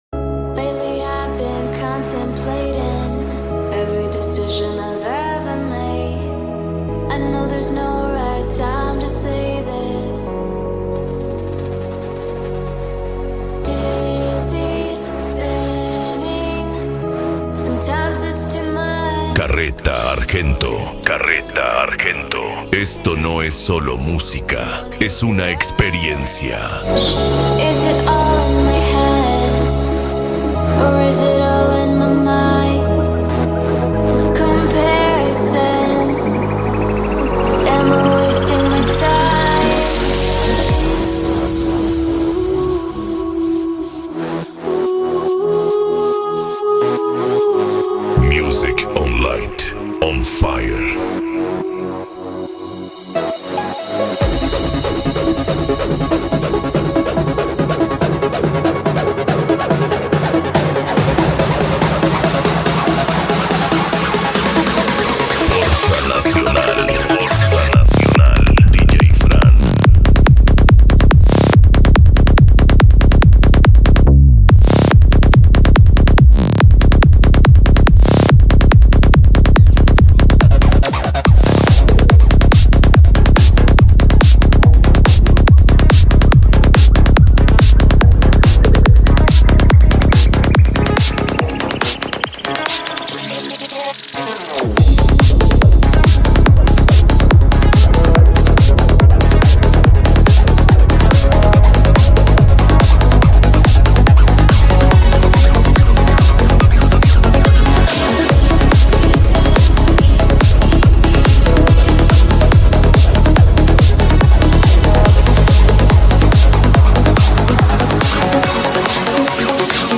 Bass
Psy Trance
Racha De Som
Remix